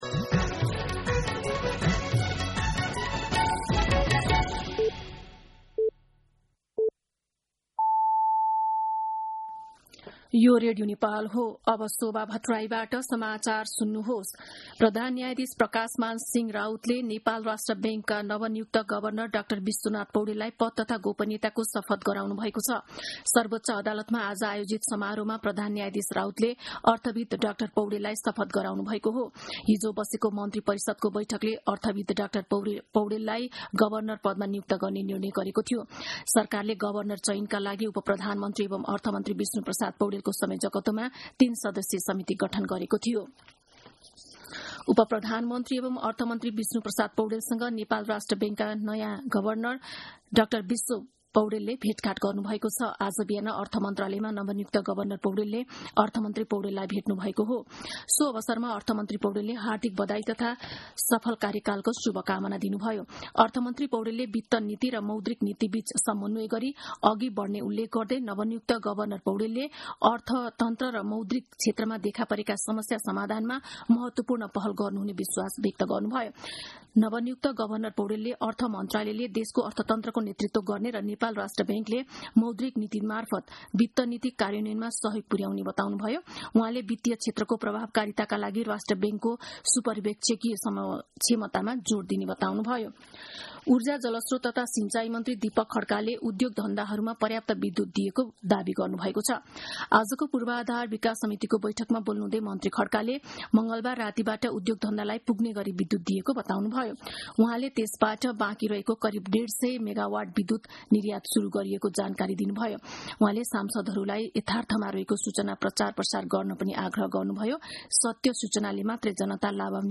दिउँसो १ बजेको नेपाली समाचार : ७ जेठ , २०८२
1-pm-Nepali-News-02-07.mp3